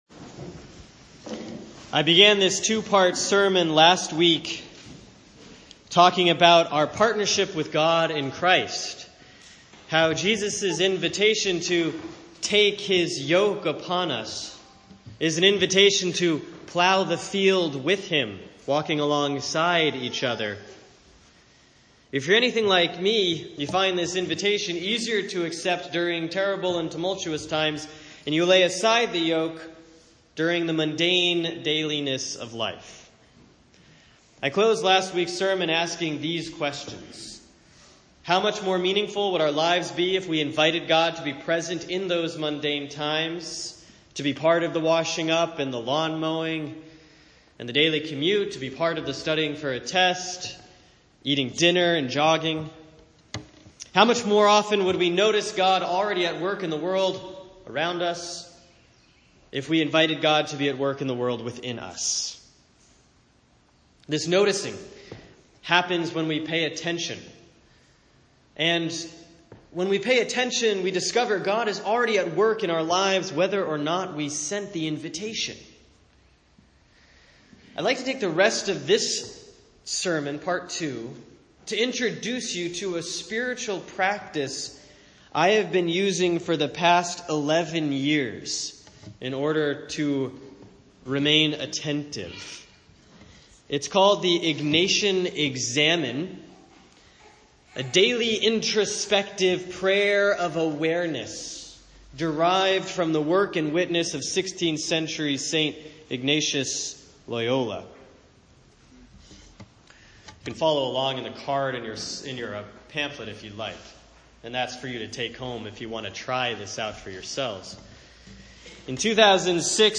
Sermon for Sunday, July 16, 2017